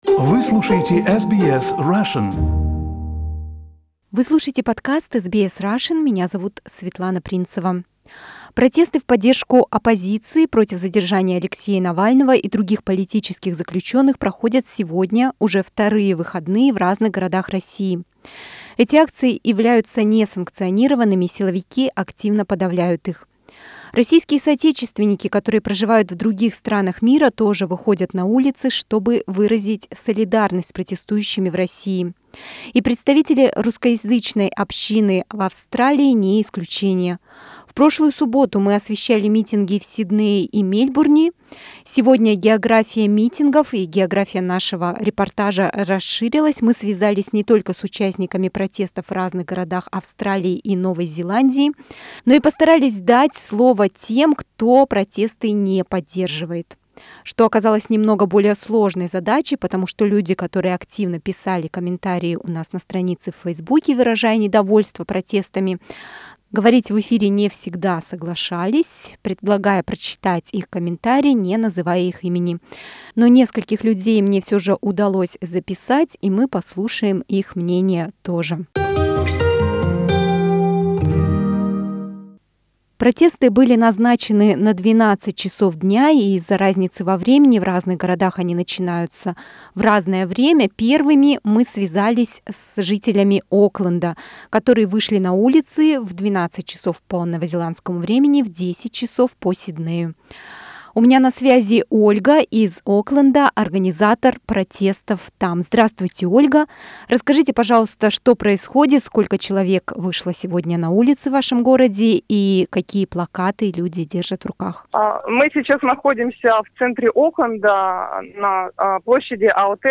Репортаж о протестах в городах Австралии и Новой Зеландии: за и против
Сегодня, 31 января 2021, митинги в поддержку оппозиции и против задержания Алексея Навального проходят не только в России, но и в других странах мира. Мы пообщались с участниками акций из Окленда (Новая Зеландия), Сиднея, Мельбурна, Брисбена и Аделаиды.